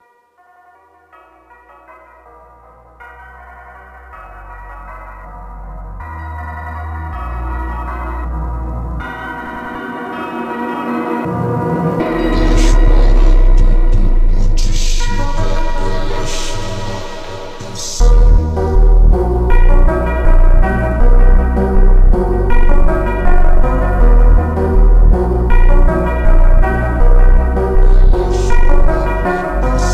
Baile Funk Brazilian
Жанр: Фанк